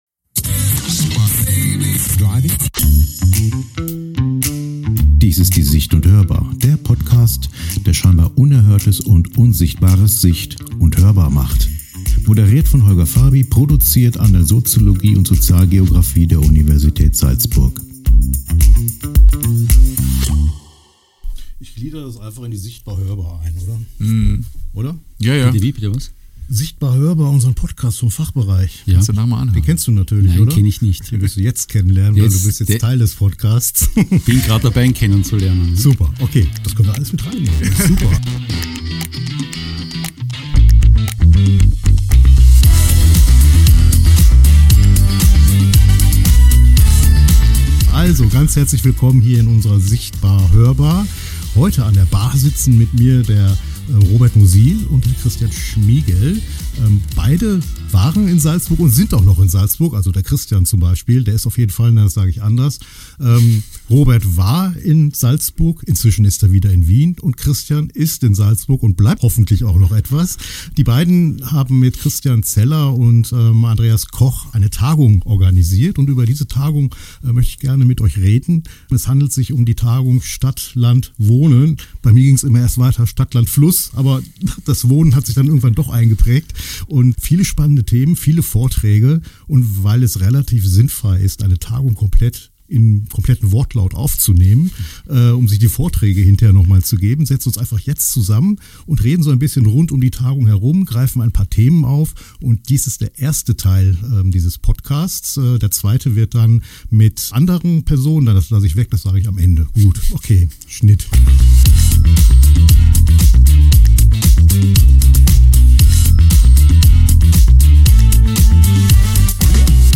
– Stadt, Land, Wohnen ohne Großstadtfilter Was passiert, wenn zwei Geographen vor einer Tagung in Salzburg in ein Podcaststudio gehen?
Vom Stadt-Land-Fluss-Versprecher zum Stadtland-Wohnen-Diskurs: eine spontane, ungebügelte Gesprächsrunde über Forschung, Politik und die sehr praktische Frage, wie viel Wohnen sich eine Gesellschaft leisten will – und wem sie’s bezahlt.